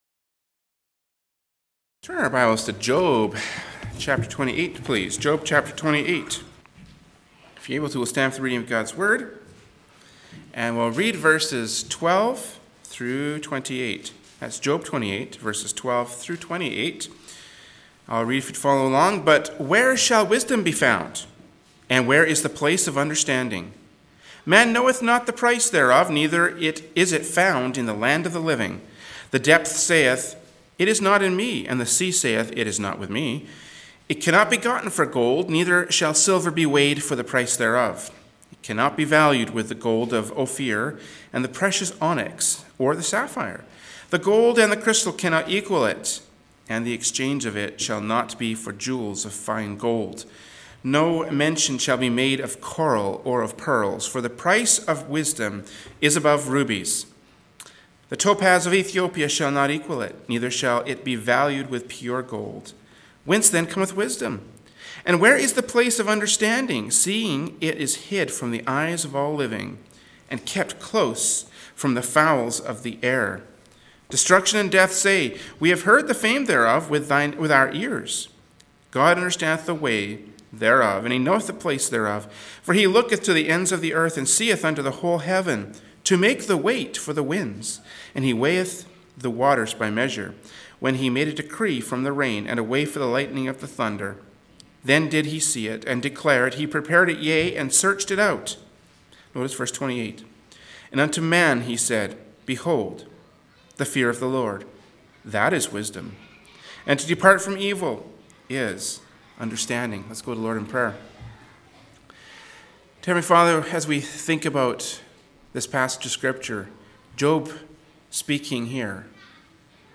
Genre: Preaching.
Job 28:12-28 Service Type: Adult Sunday School “Job 28:12-28” from Sunday School Service by Berean Baptist Church.